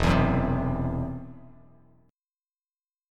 Esus2#5 chord